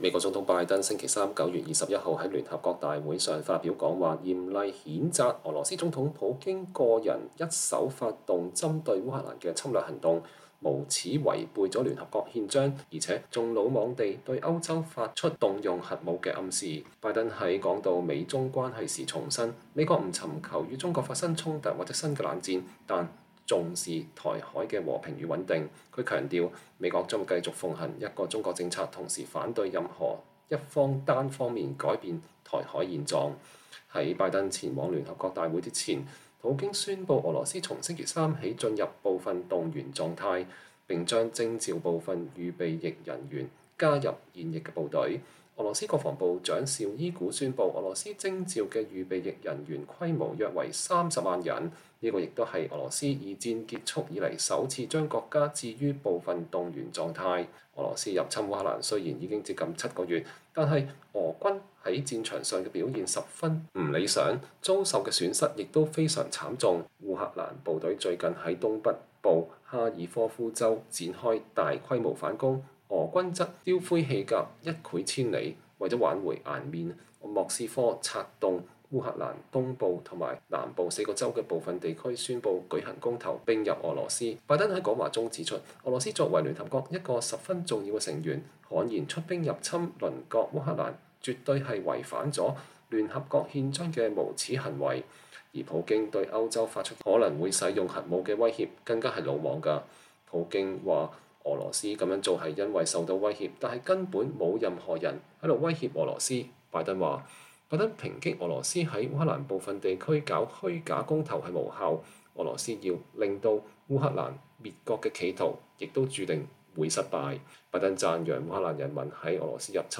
美國總統拜登2022年9月21日在紐約聯合國第76屆大會上發表講話。